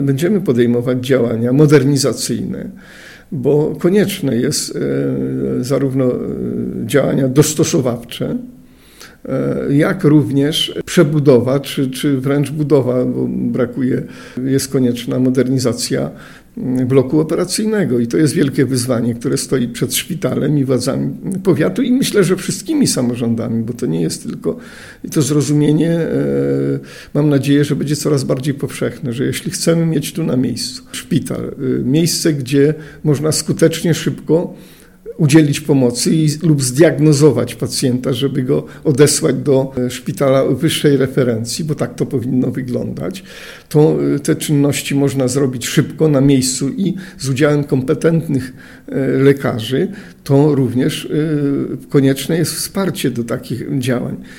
WYSŁUCHAJ całej rozmowy z Józefem Matysiakiem, starostą powiatu rawskiego TUTAJ>>>